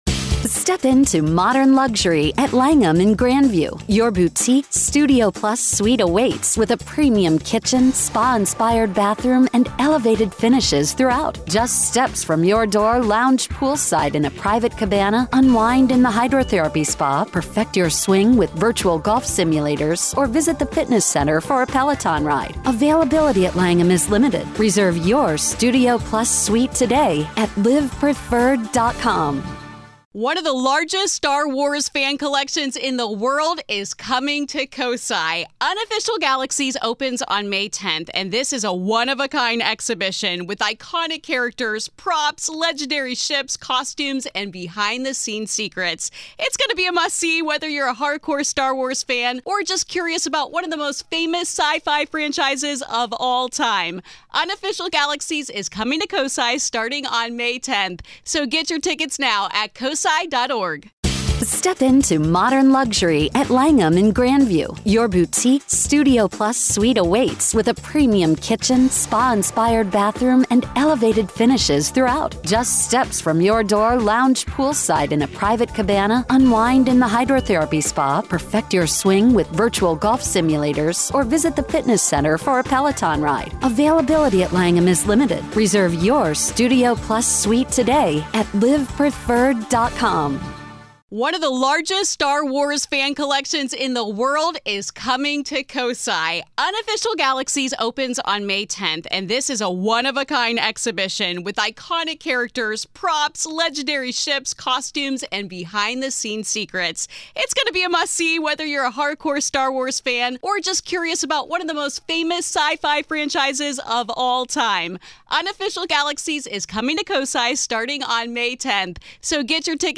In this touching episode, one caller shares how love, grief, and the supernatural have intertwined in the wake of their loss.